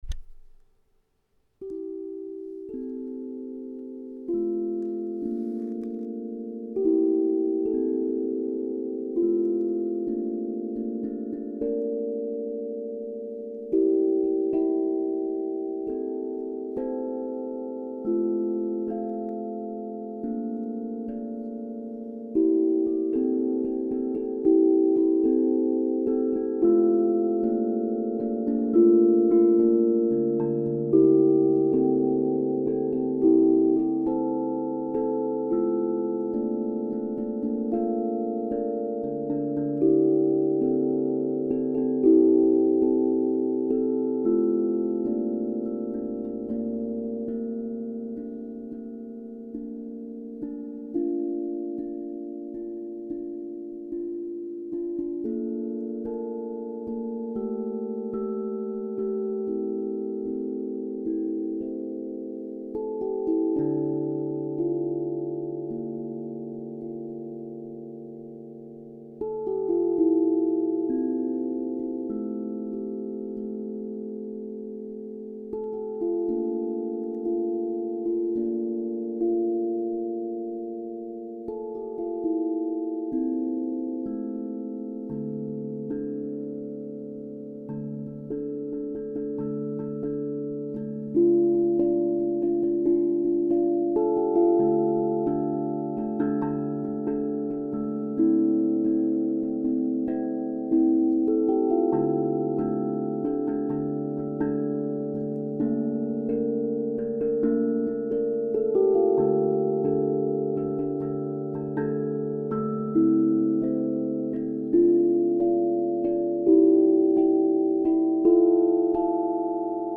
Ré Kurd 432 hz L'empreinte - Osb Drum
La gamme Kurd est l'une des plus célèbre dans le monde du drum mélodique, on ne la présente plus chez les joueurs de handpan. Elle est mineure mais contient quasiment tout le pannel majeur diatonique.
d-kurd-432-hz-lempreinte.mp3